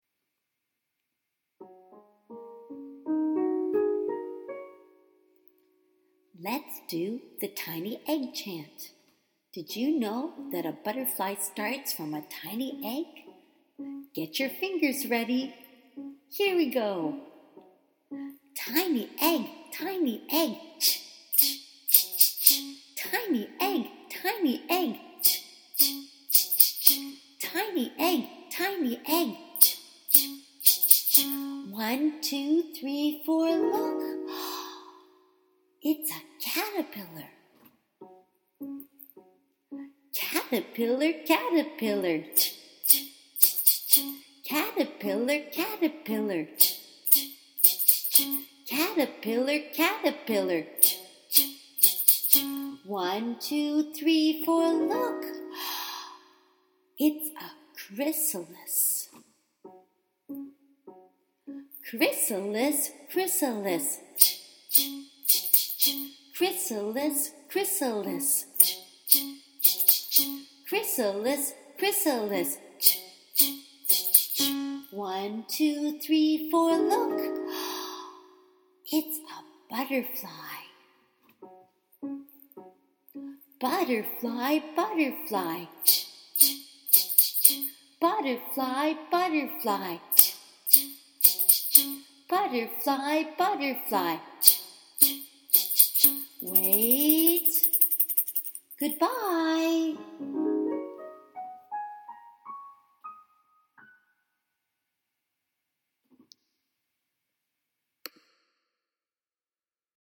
perform a chant
Here is a simple recording of the chant that you can use: